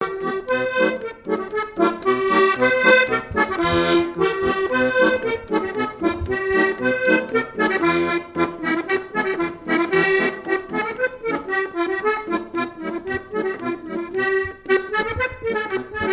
Couplets à danser
danse : polka piquée